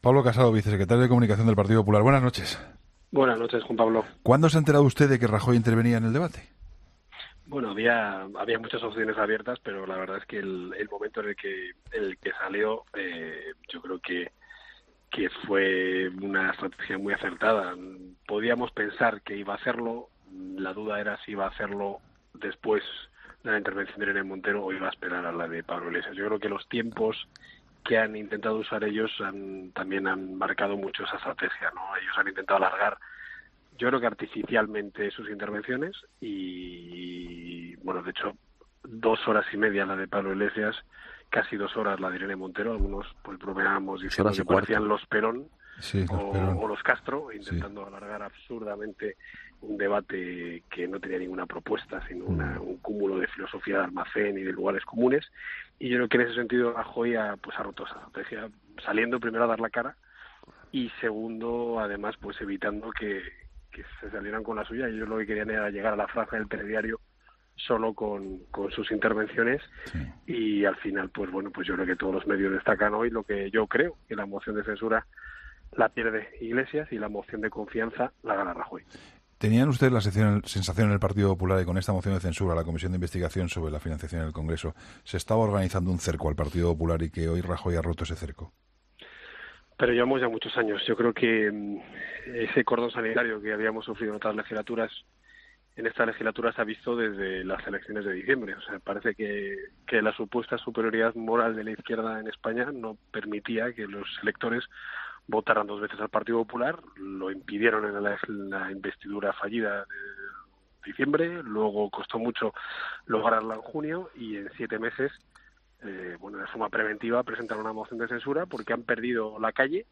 Entrevista a Pablo Casado